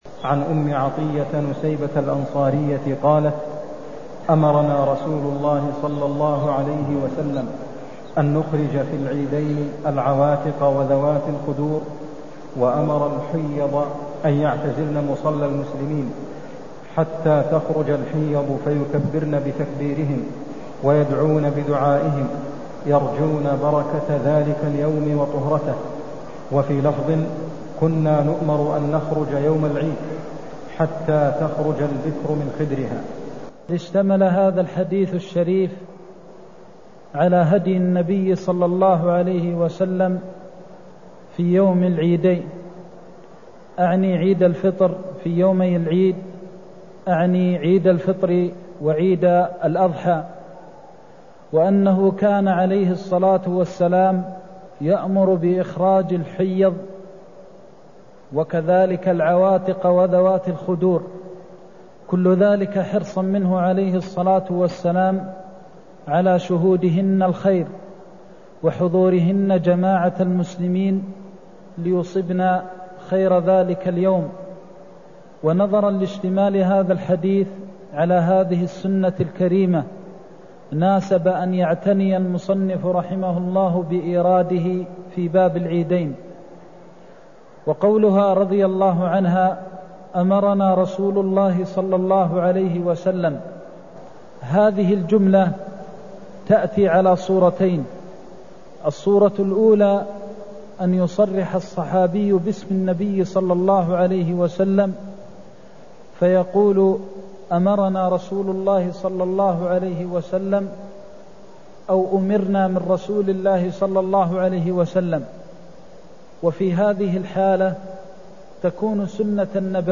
المكان: المسجد النبوي الشيخ: فضيلة الشيخ د. محمد بن محمد المختار فضيلة الشيخ د. محمد بن محمد المختار الخروج إلى صلاة العيد (140)